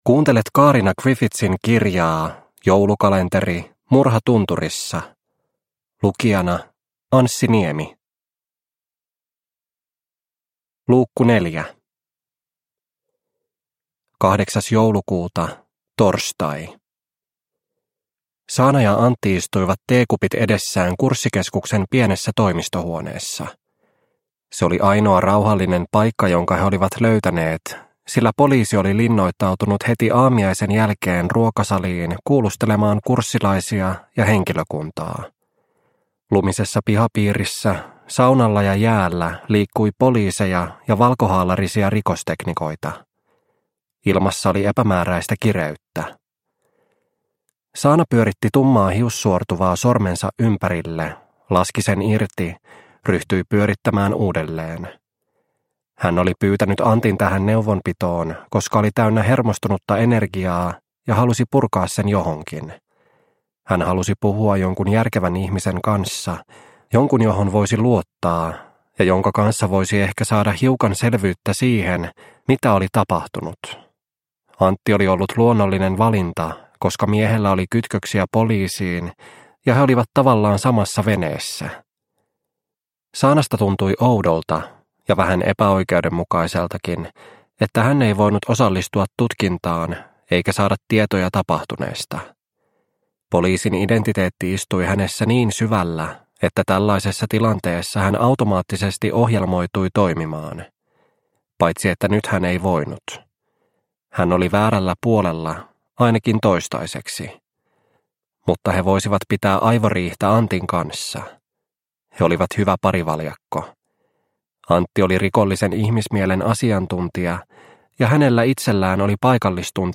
Murha tunturissa - Osa 4 – Ljudbok – Laddas ner